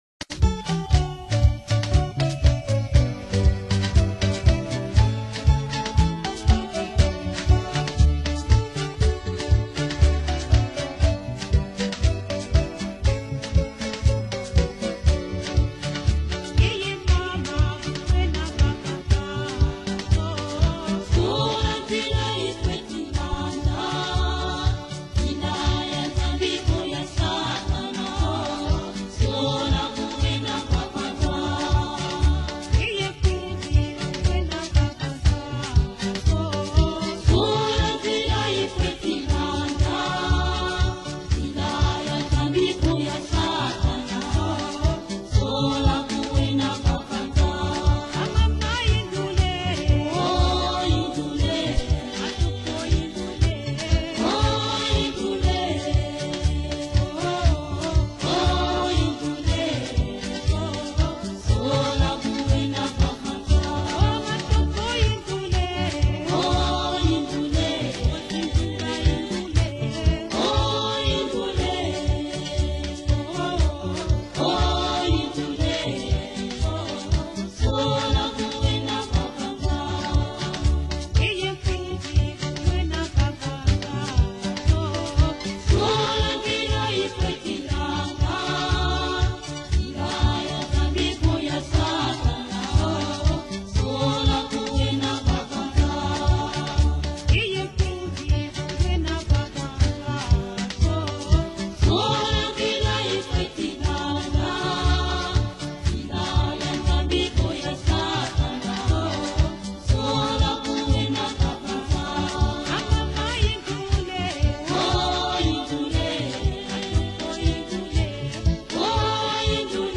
Gospel 2003